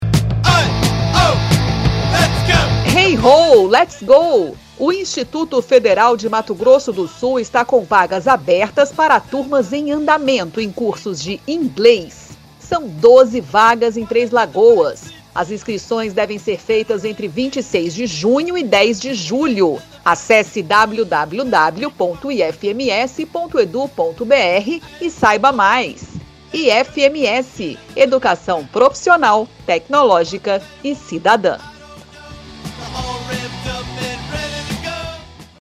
Spot - Cursos de Idiomas em Três Lagoas